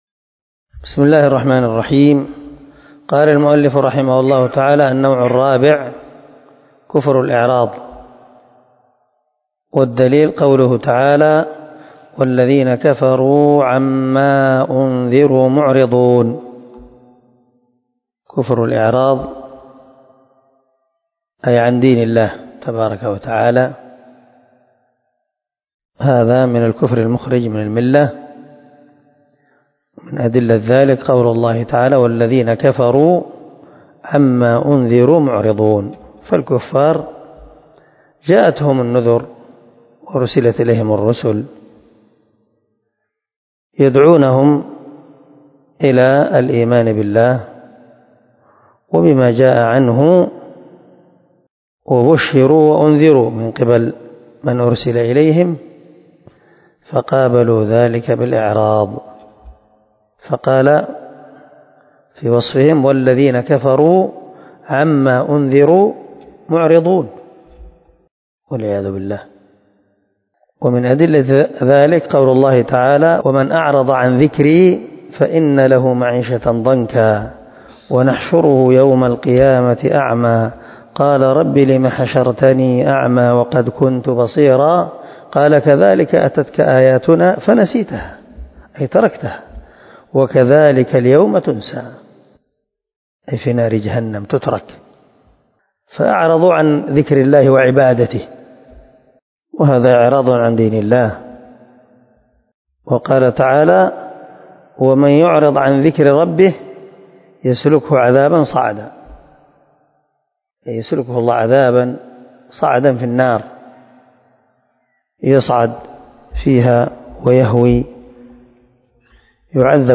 🔊الدرس 34 النوع الرابع كفر الاعراض